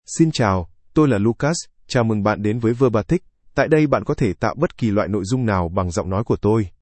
MaleVietnamese (Vietnam)
LucasMale Vietnamese AI voice
Voice sample
Lucas delivers clear pronunciation with authentic Vietnam Vietnamese intonation, making your content sound professionally produced.